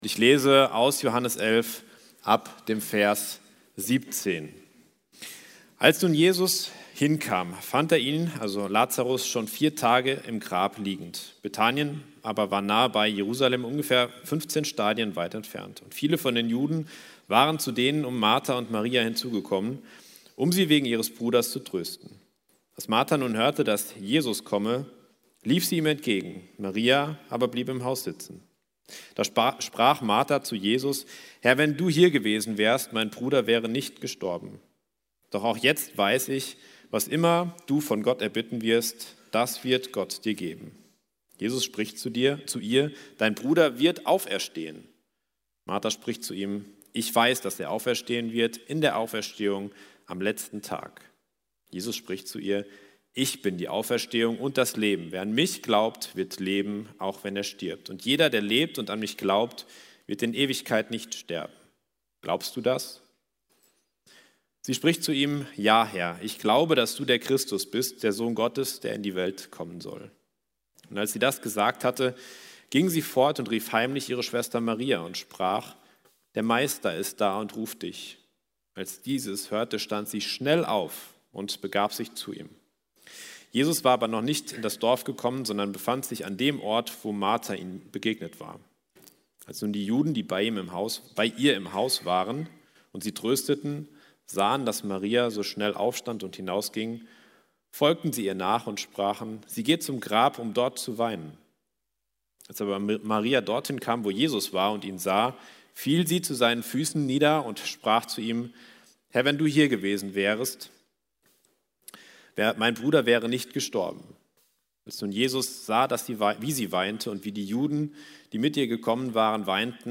Predigten – Evangelische Gemeinschaft Kredenbach